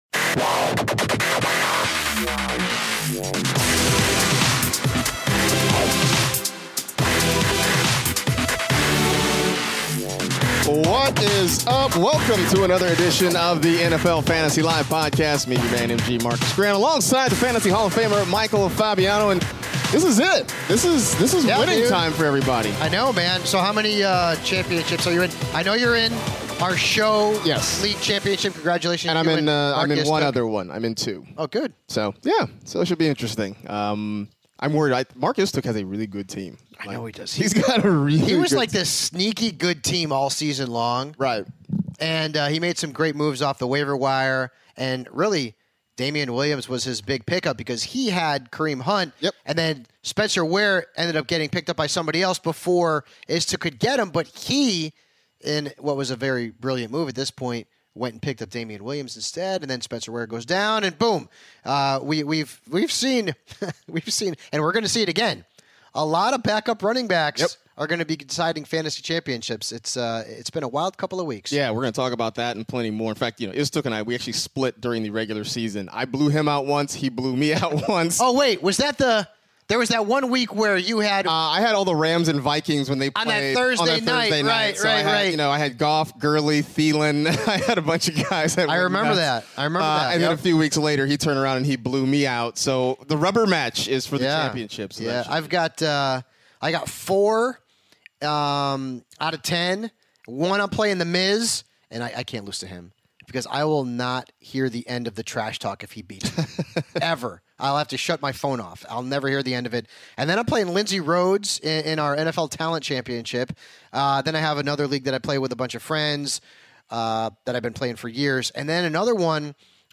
are in studio to preview everything fantasy football for Week 16! The guys start off with the biggest news headlines like Josh Gordon stepping away from the Patriots to focus on his mental health and the Panthers shutting down Cam Newton for the remainder of the season (3:45).